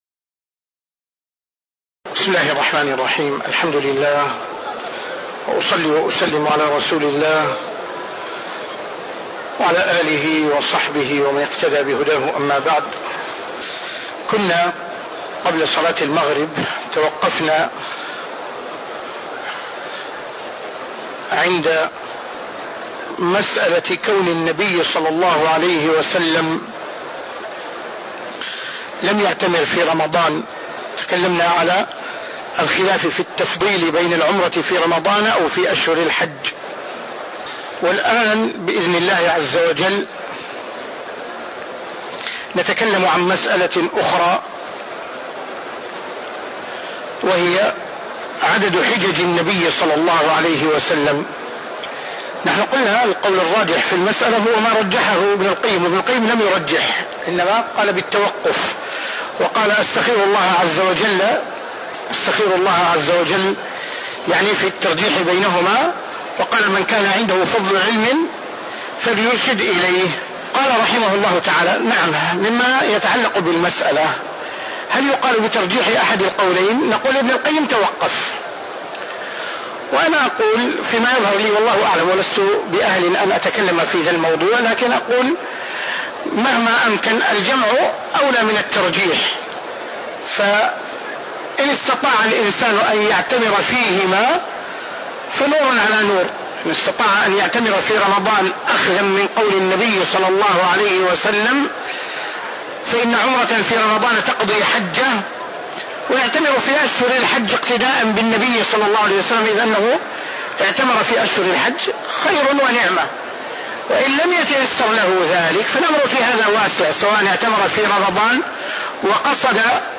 تاريخ النشر ٥ ذو القعدة ١٤٣٧ هـ المكان: المسجد النبوي الشيخ